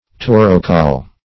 Taurocol \Tau"ro*col\